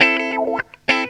GTR 5  AM.wav